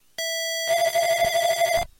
描述：带32mb卡和i kimu软件的gameboy样品